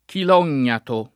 vai all'elenco alfabetico delle voci ingrandisci il carattere 100% rimpicciolisci il carattere stampa invia tramite posta elettronica codividi su Facebook chilognato [ kilon’n’ # to ; raro kil 0 n’n’ato ] s. m. (zool.)